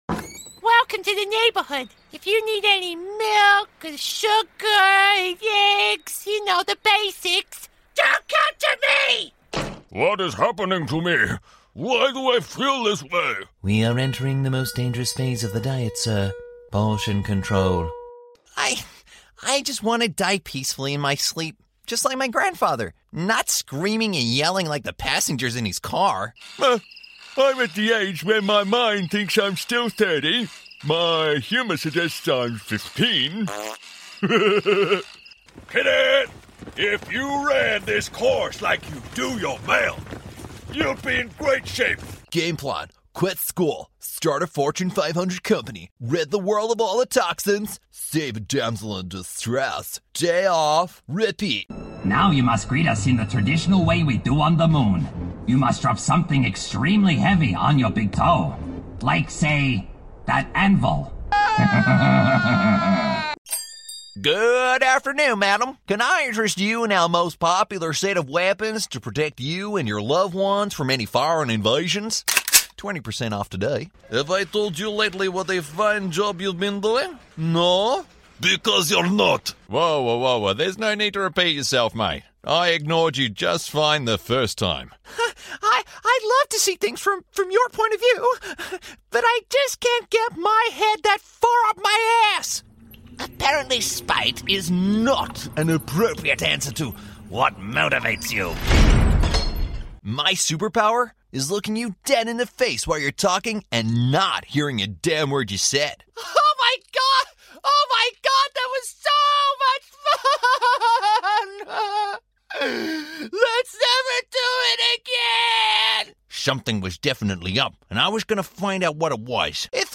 Male
Character / Cartoon
Loads Of Characters